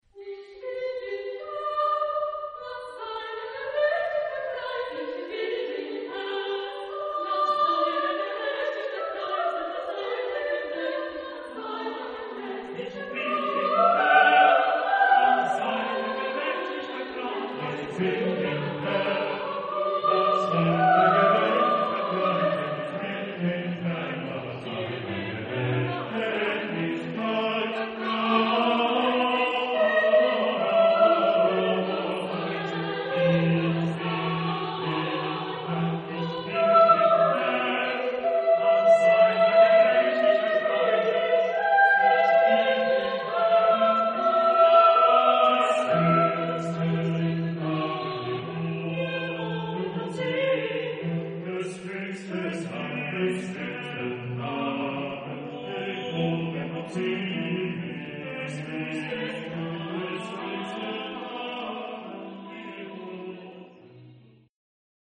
Genre-Style-Forme : Sacré ; Motet ; Psaume
Type de choeur : SATB  (4 voix mixtes )
Tonalité : sol majeur